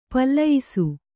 ♪ pallayisu